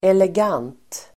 Uttal: [eleg'an:t (el. -'ang:t)]